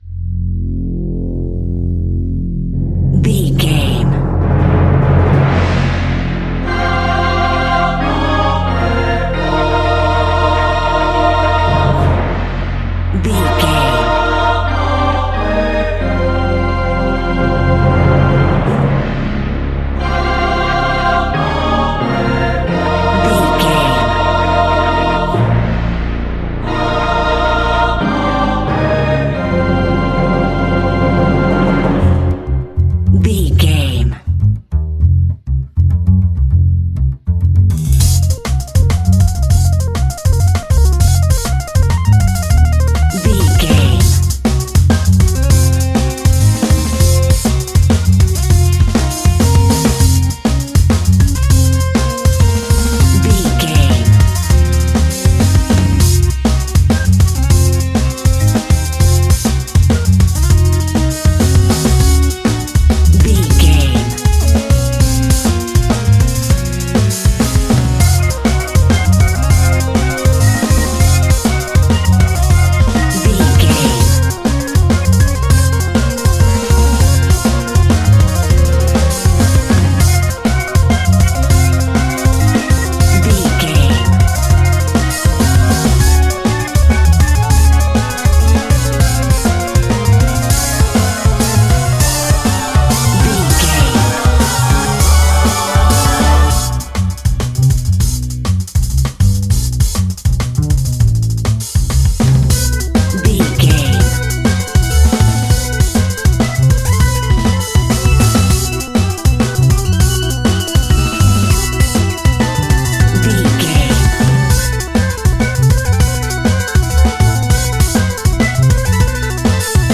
Phrygian
Fast
aggressive
driving
dark
dramatic
energetic
intense
epic
vocal
strings
brass
drums
orchestra
electric organ
synthesiser
cello